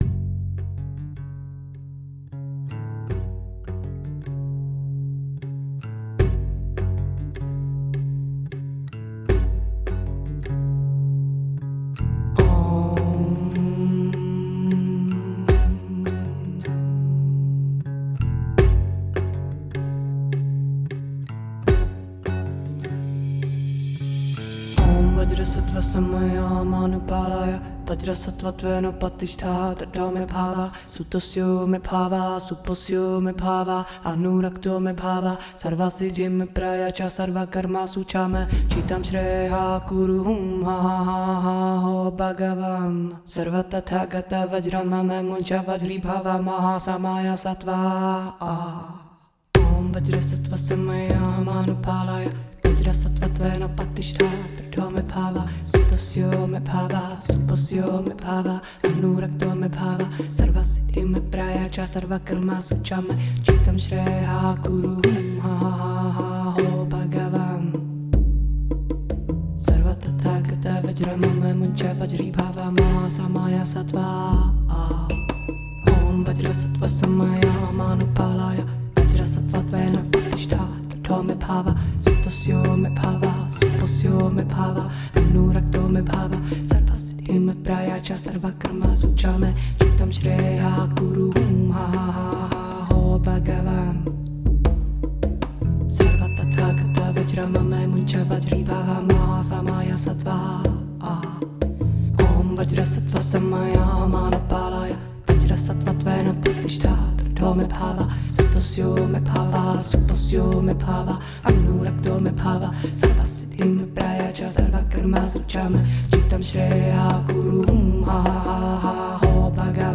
藏傳與漢傳《金剛薩埵 百字明》的梵音唱誦
藏傳《金剛薩埵百字明》梵音：
100-syllable-VAJRASATTVA-MANTRA.mp3